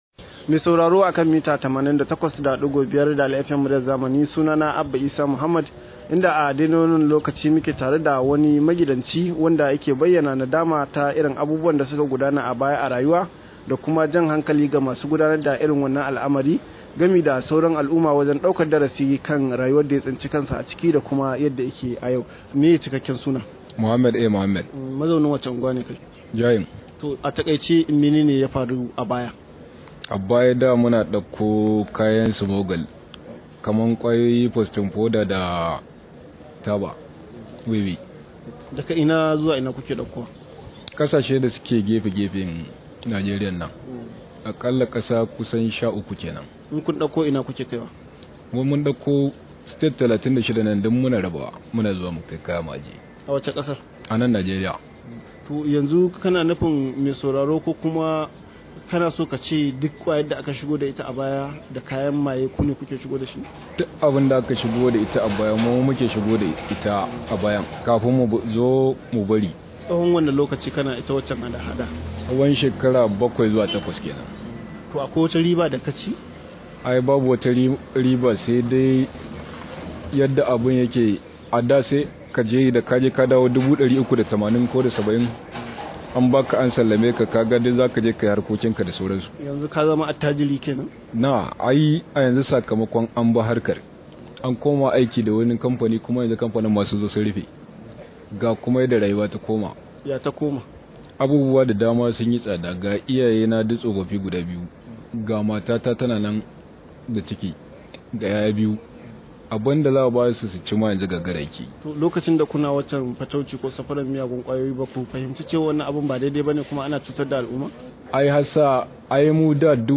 Rahoto: Shekara 8 na yi ina murkushe mutane a kan hanya – Magidanci